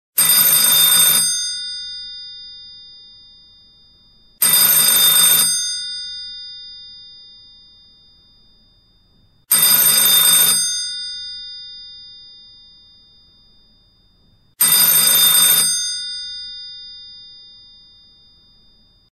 Categories Electronic Ringtones